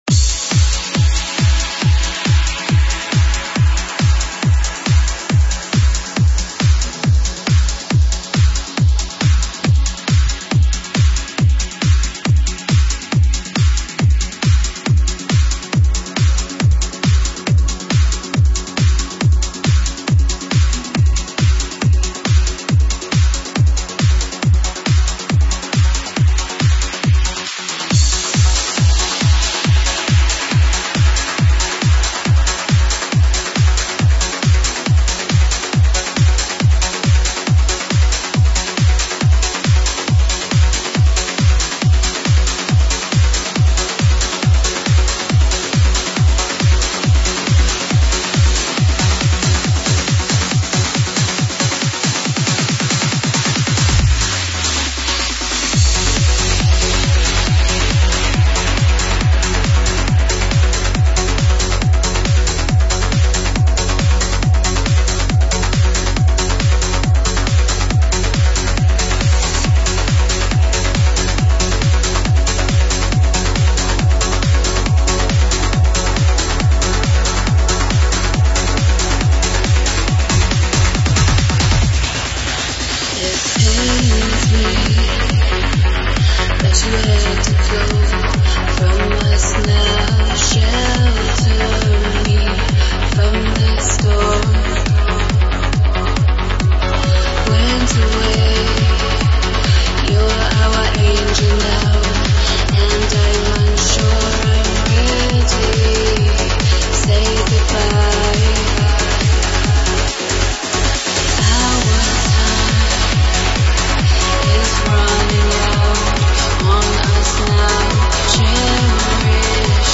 Стиль: Vocal Trance / Uplifting Trance